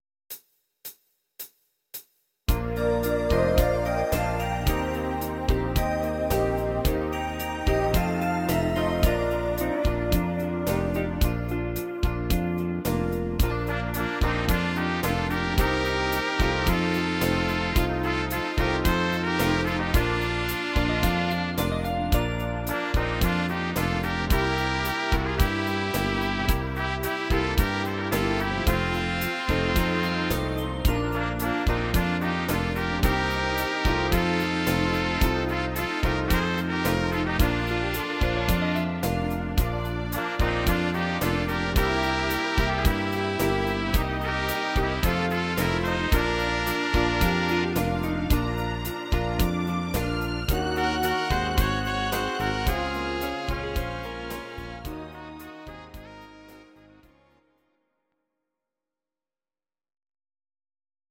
Audio Recordings based on Midi-files
instr. Orchester